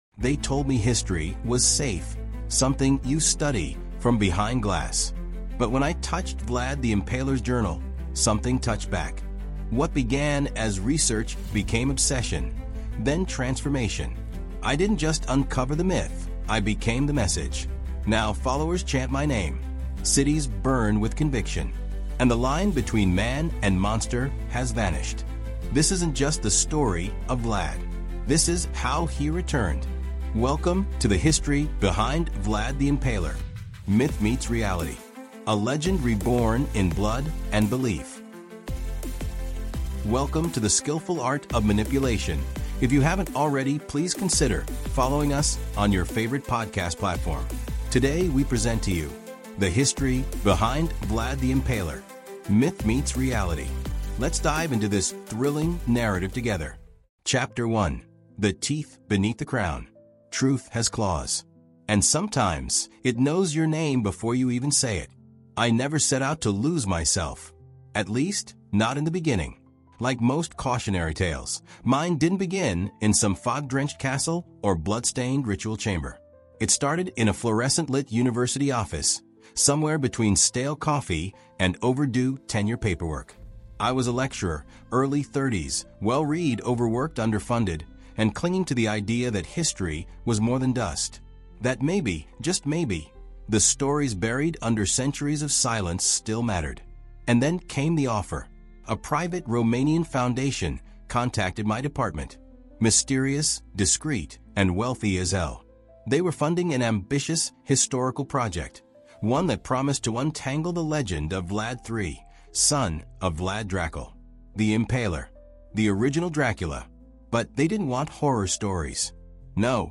The History Behind Vlad the Impaler: Myth Meets Reality | Audiobook
Told in a raw, first-person format, this audiobook unravels the legacy of Vlad not through battles—but through belief, control, and myth made flesh.